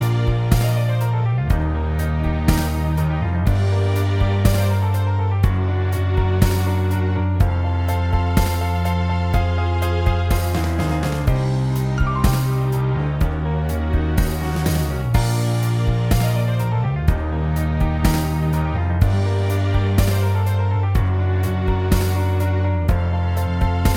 Minus Solo Guitar Pop (1980s) 3:32 Buy £1.50